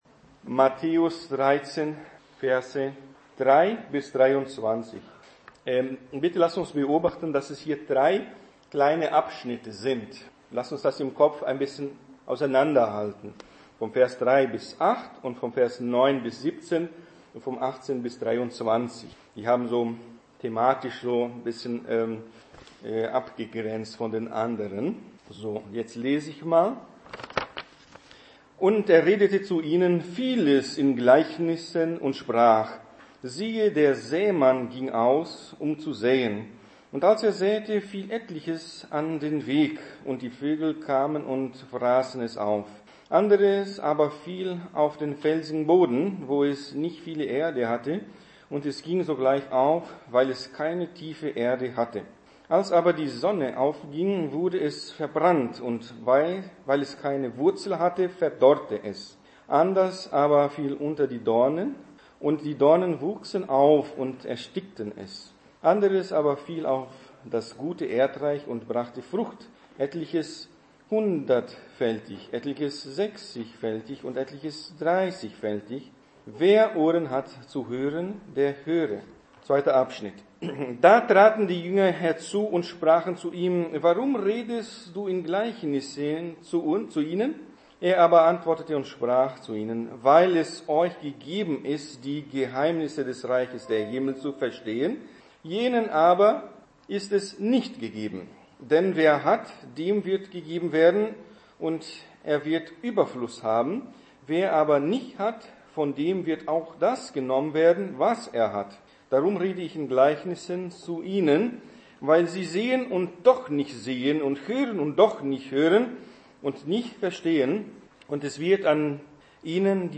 Der Sämann / Predigt